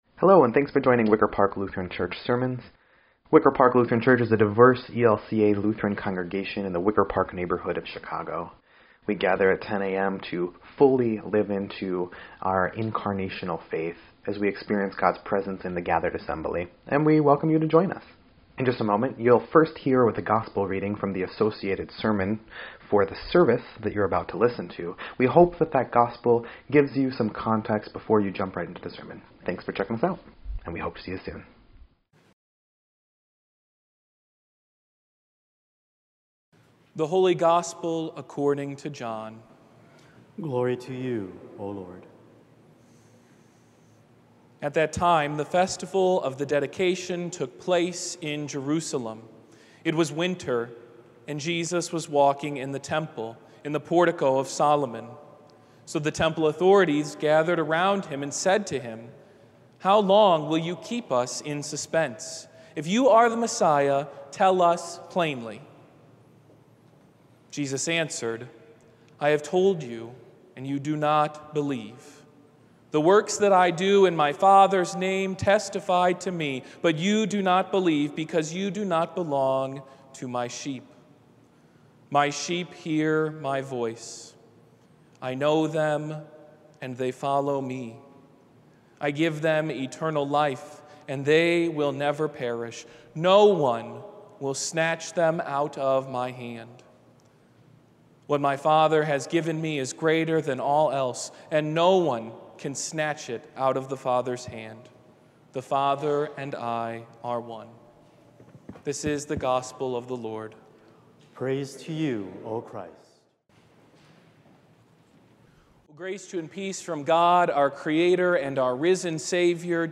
5.8.22-Sermon_EDIT.mp3